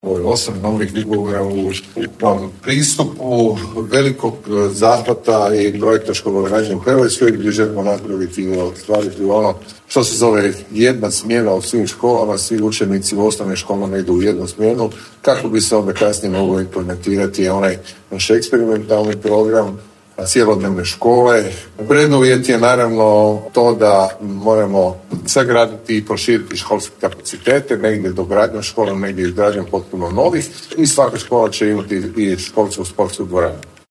Detaljniji uvid u projekte koji se tiču ulaganja u školstvo pružio je ministar Radovan Fuchs